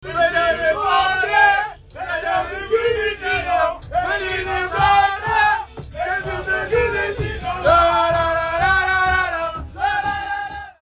Calciatori del Milan sono indagine della Fgci per aver cantato sull’autobus dopo la partita contro la Roma un coro contro la Juve:
CORO-ANTI-JUVE-19.30.mp3